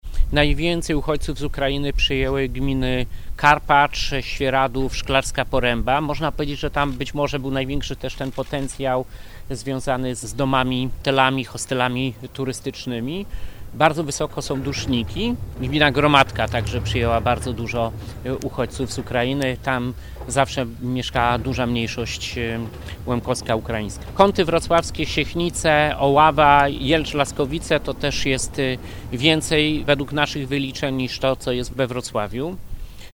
– Przez samorządy została wykonana bardzo duża praca – dodał wojewoda.